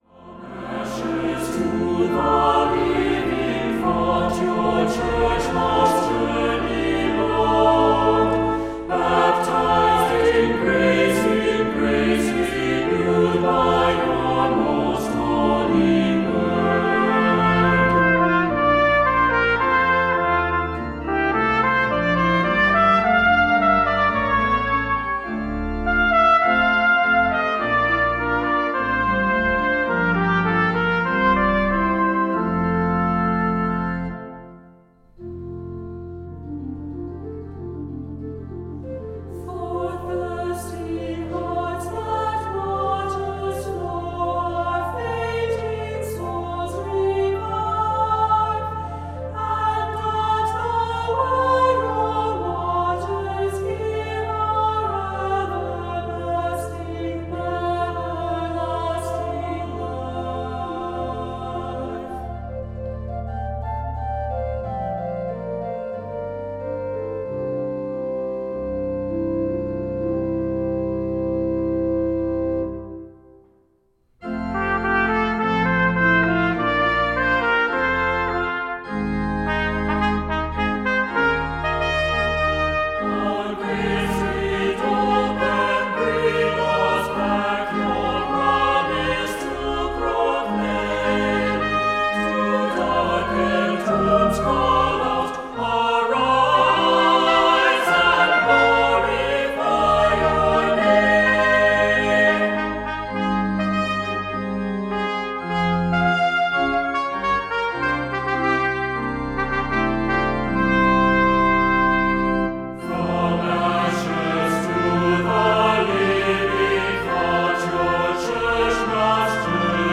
Voicing: SATB; optional Assembly